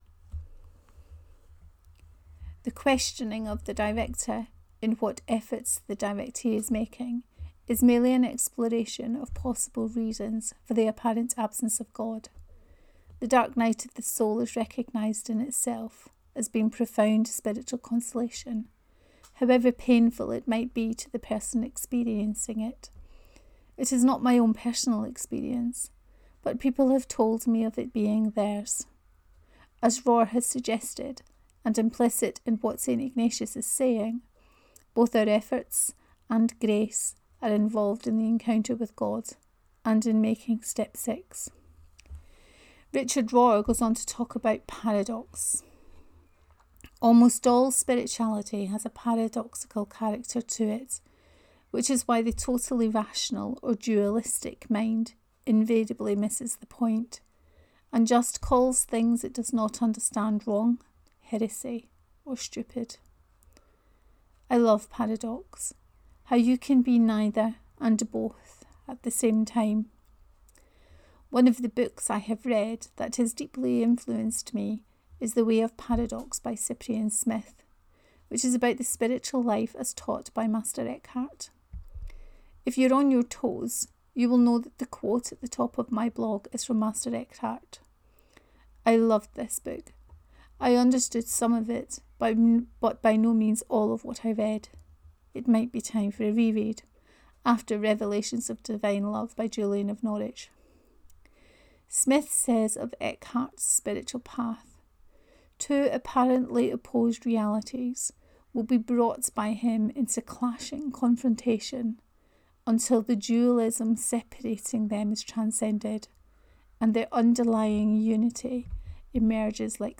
Were entirely ready…2b: Reading of this post